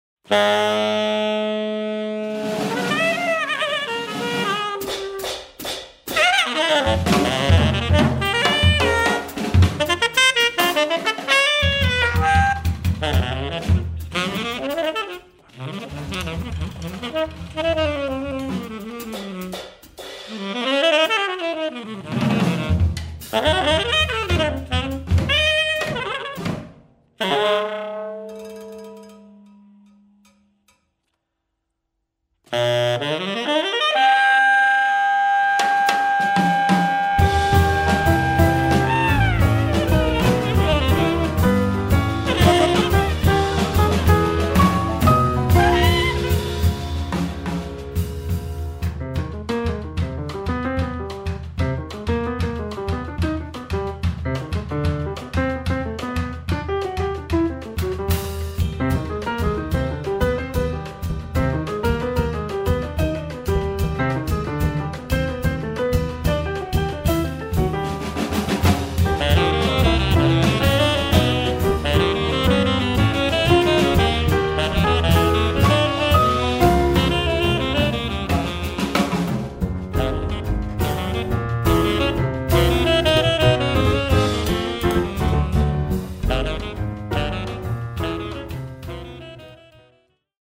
saksofoni
kitare
klavir
tuba
bobni